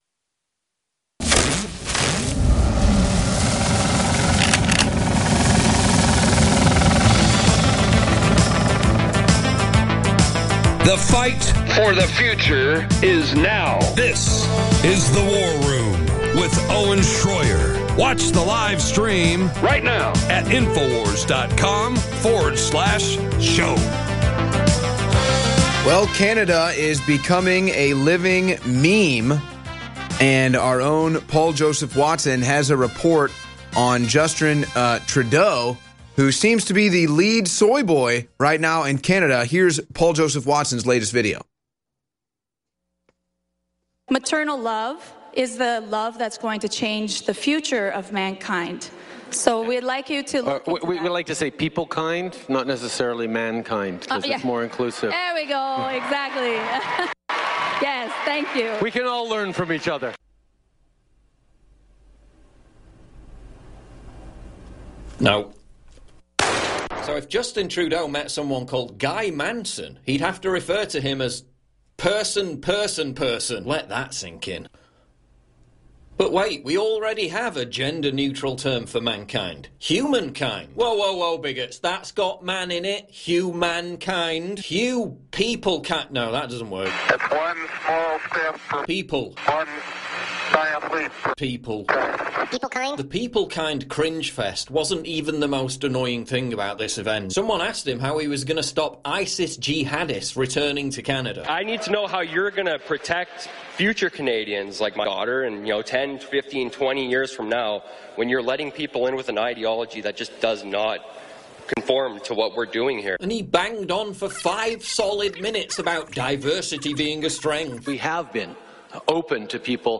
We also get the rare opportunity to respond to a liberal caller.